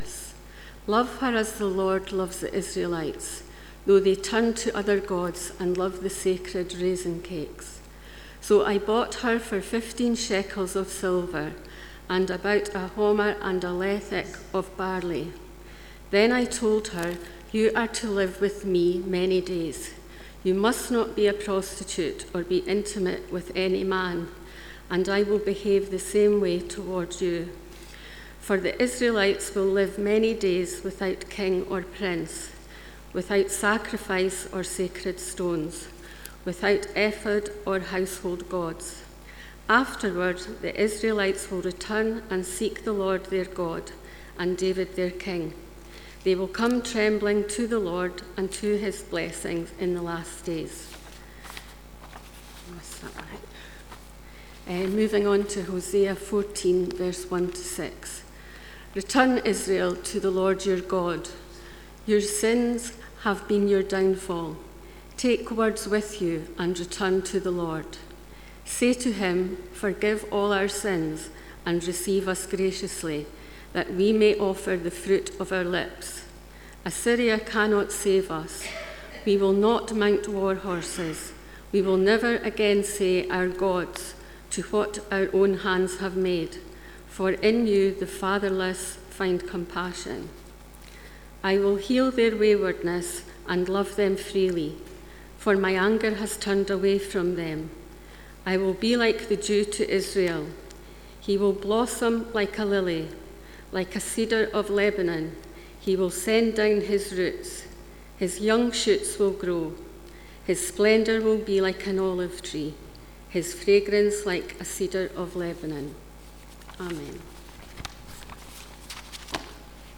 Passage: Hosea 3:1-5, 11:1-4, 14:1-6 Service Type: Sunday Morning « Pentecost